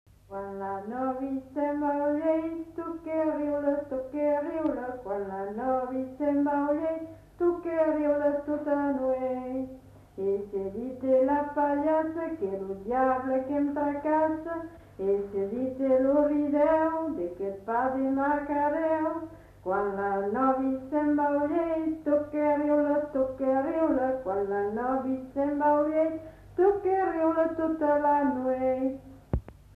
[Brocas. Groupe folklorique] (interprète)
Aire culturelle : Marsan
Genre : chant
Type de voix : voix de femme
Production du son : chanté
Description de l'item : fragment ; 1 c. ; refr.